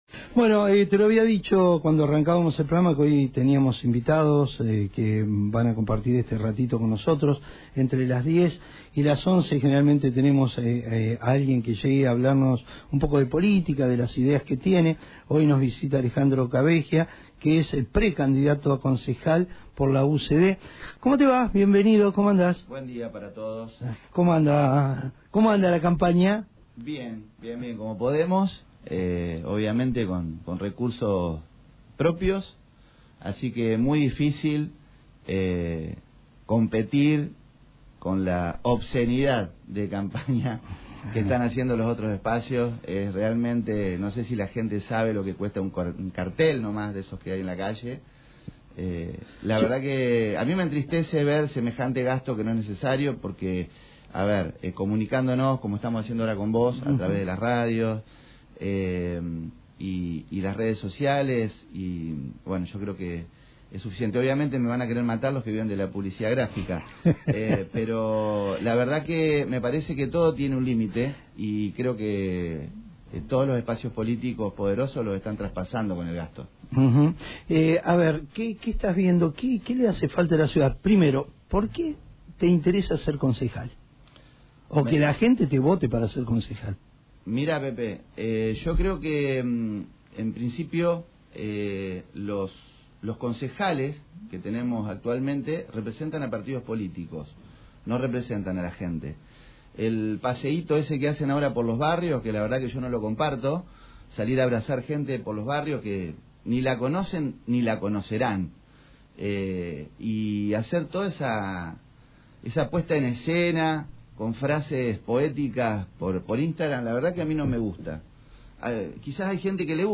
en los estudios de Radio de Noticias